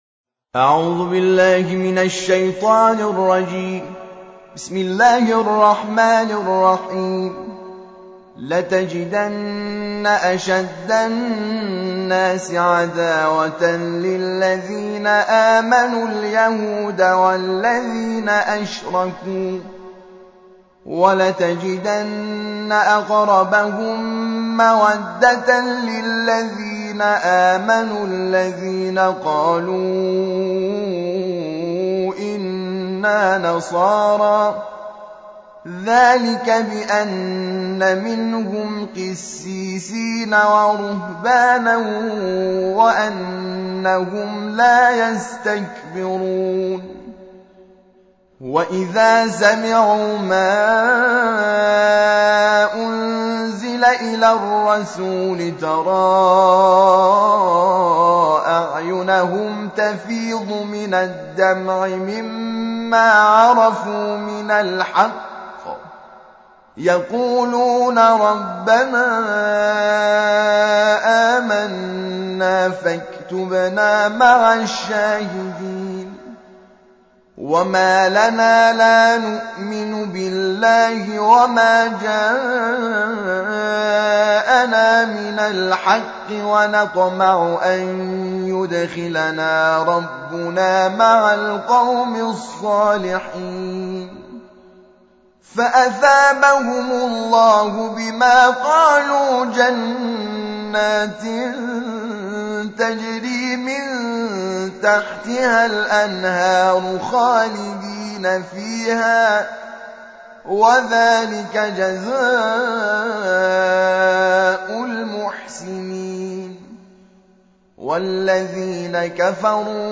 تحميل : الجزء السابع / القارئ حامد شاكر نجاد / القرآن الكريم / موقع يا حسين